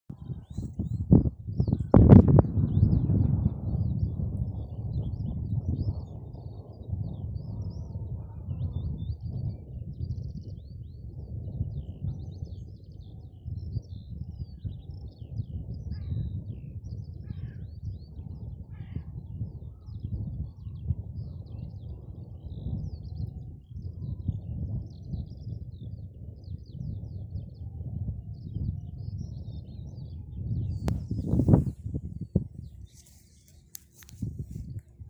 Birds -> Larks ->
Skylark, Alauda arvensis
StatusSinging male in breeding season